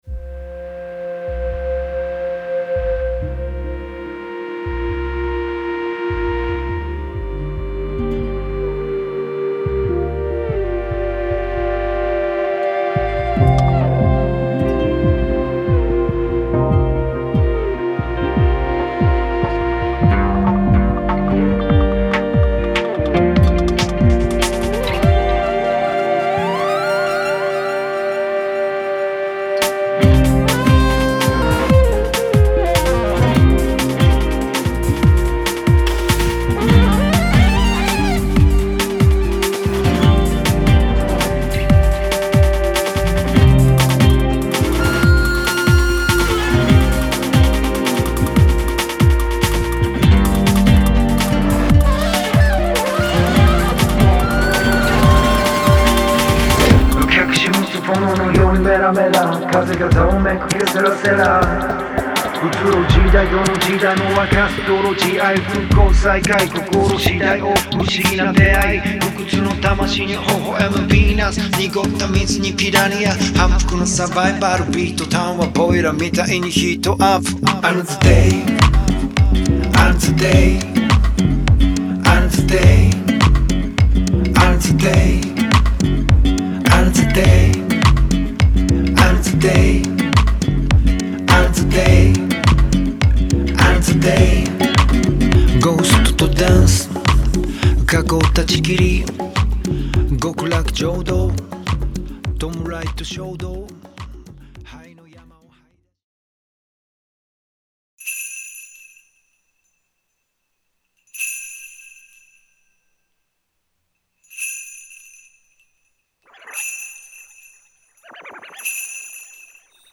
心がけたのは、ピッチが徐々に上がっていく歌い出しを設けたり、遊び心をもって柔軟に表現する事。
聴けば懐かしささえある異国のAMAPIANO。
本来なら長い時間をかけて昇華していくAMAPIANOだが2分という短さで吹き抜けていく。